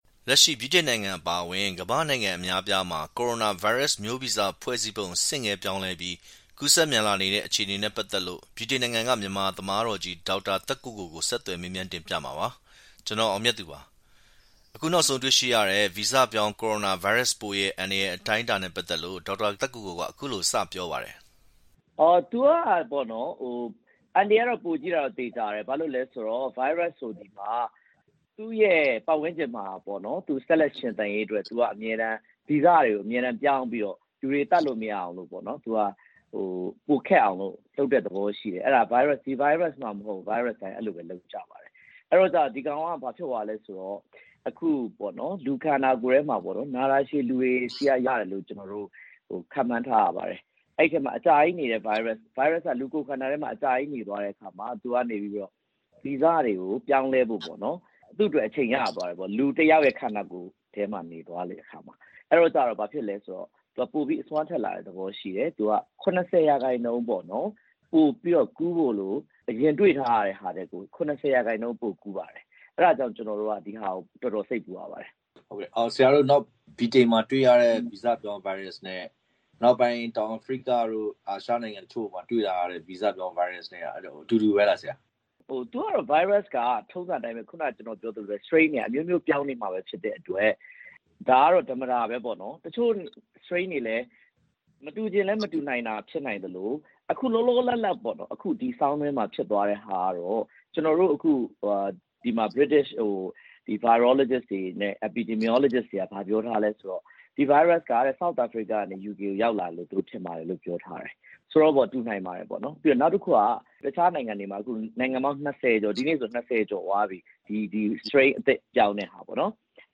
ဗီဇပြောင်း ကိုရိုနာဗိုင်းရပ် နှင့် ကာကွယ်ဆေးအလားအလာ (မြန်မာသမားတော်နဲ့ဆက်သွယ်မေးမြန်းချက်)